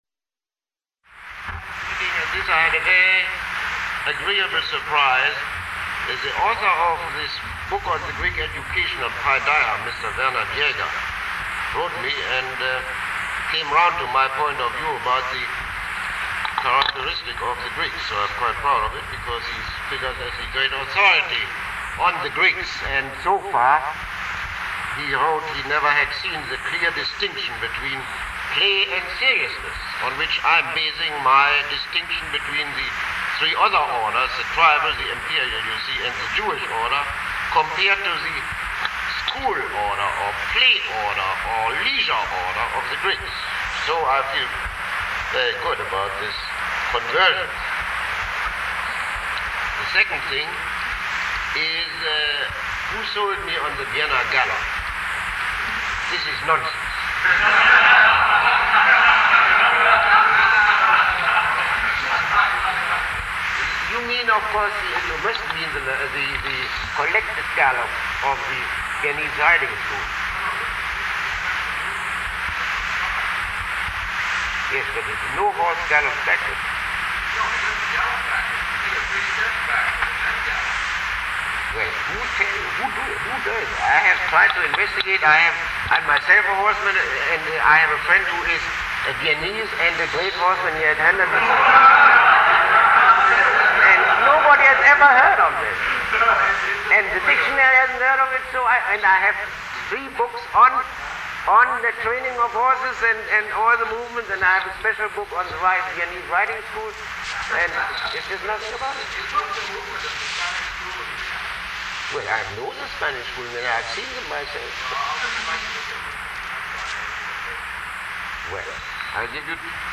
Lecture 21